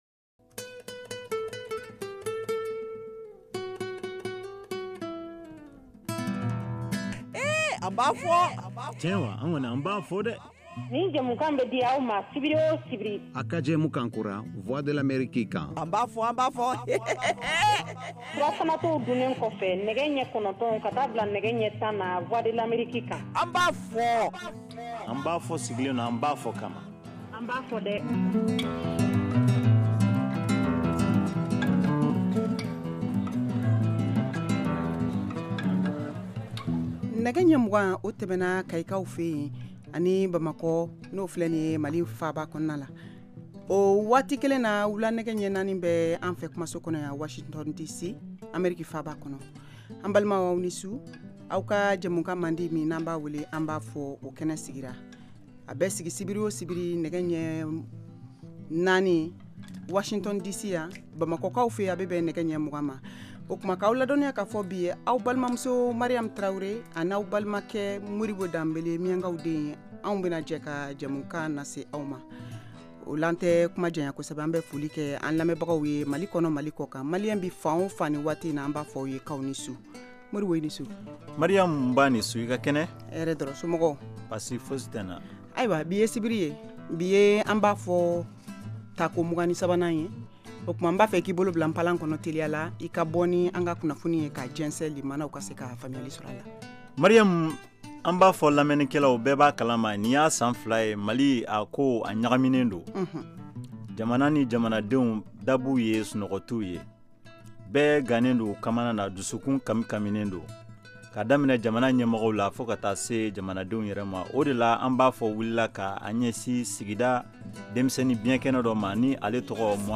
An ba fɔ! est une nouvelle émission interactive en Bambara diffusée en direct tous les samedis, de 20:00 à 21:00 T.U.